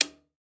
breaker_switch.ogg